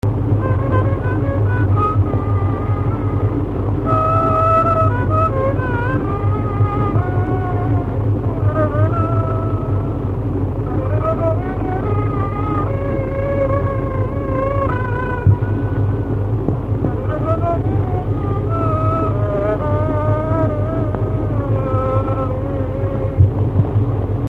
Dallampélda: Hangszeres felvétel